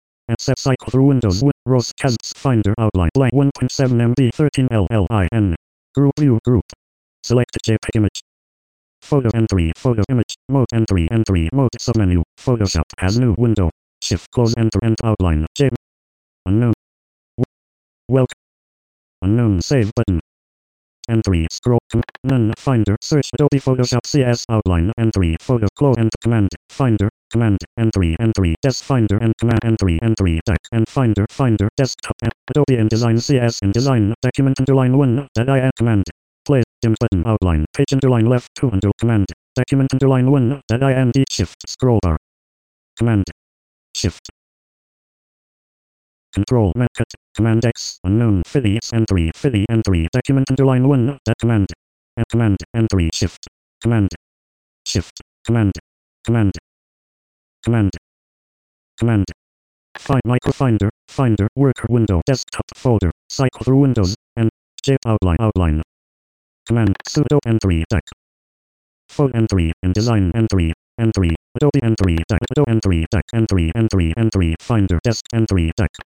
And for added interest (!), here's the sound of me laying out an article, as interpreted by the VoiceOver utility for blind people: